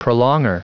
Prononciation du mot prolonger en anglais (fichier audio)
Prononciation du mot : prolonger